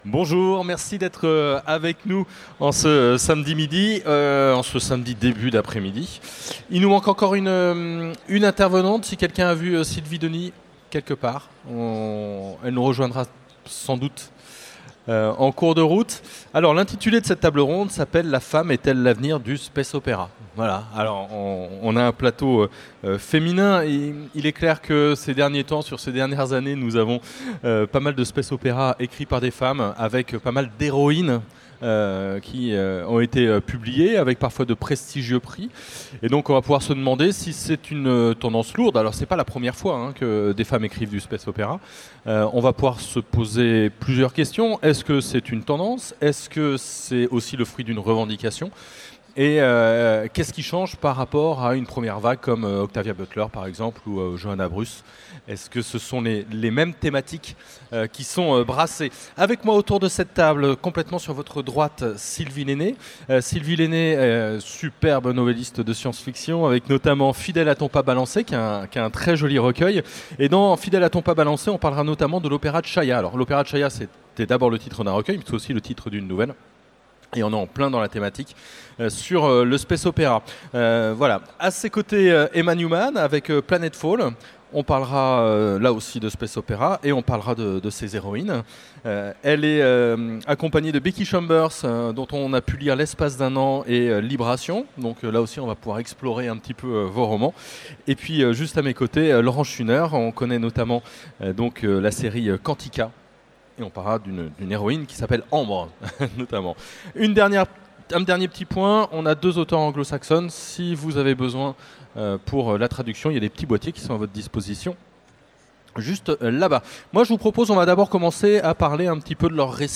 Utopiales 2017 : Conférence La femme est-elle l’avenir du space opera ?